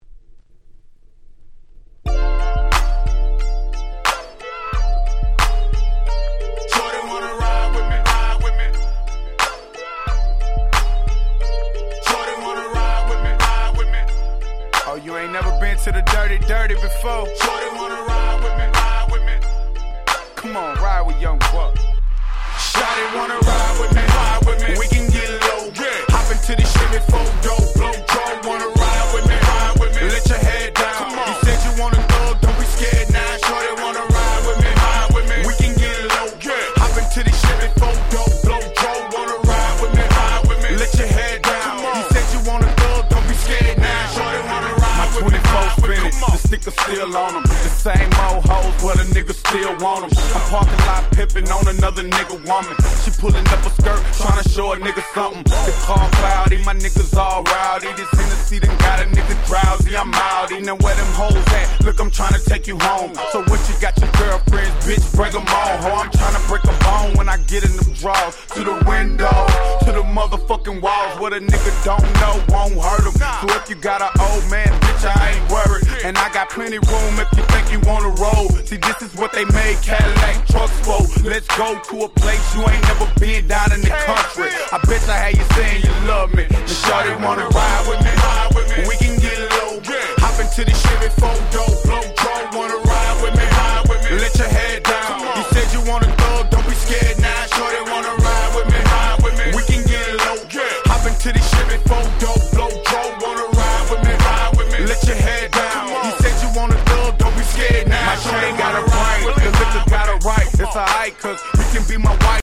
04' Super Hit Hip Hop !!
得意のBounce BeatにキレッキレのFlowが炸裂した非常に格好良い1曲です！！
00's South